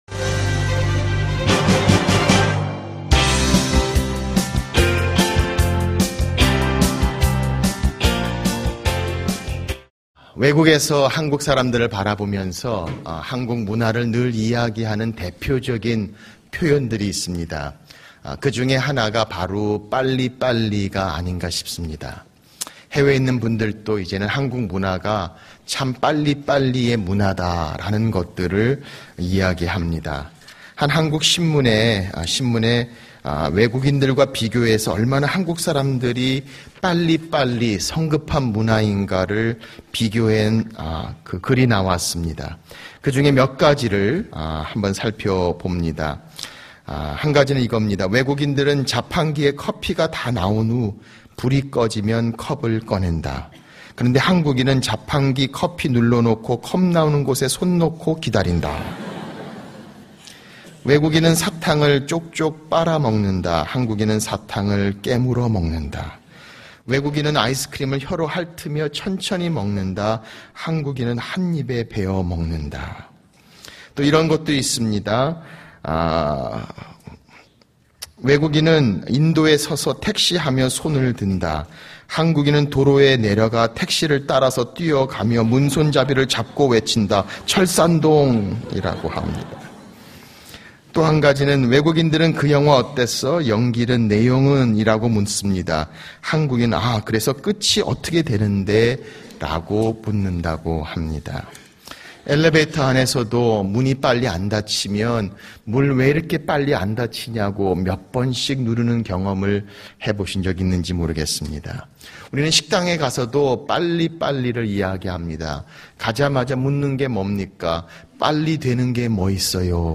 설교 | (13) 인내, 할 수 있습니까?